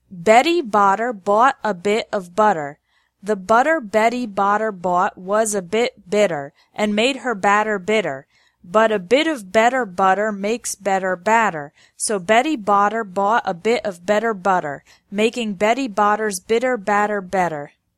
Pronunciation Practice with Tongue Twisters
Slow: